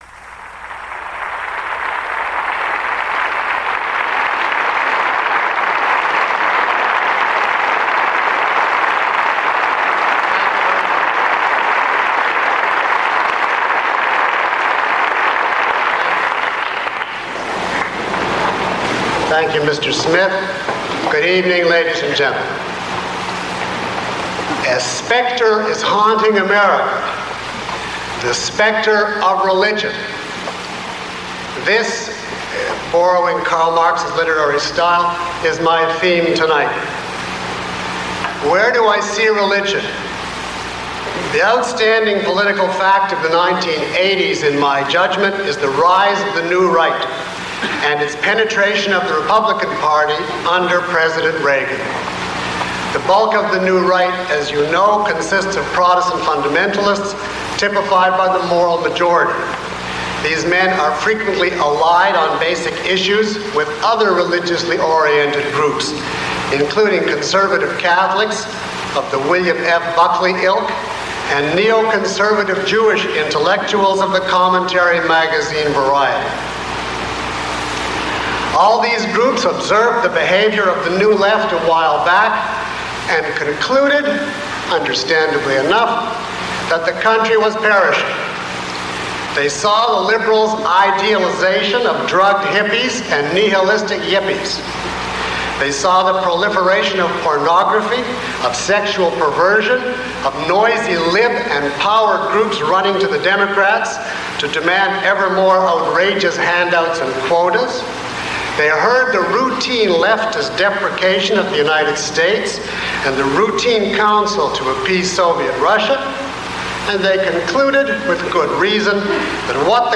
Lecture (MP3) Questions about this audio?
Below is a list of questions from the audience taken from this lecture, along with (approximate) time stamps.